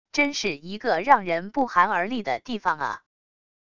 真是一个让人不寒而栗的地方啊wav音频生成系统WAV Audio Player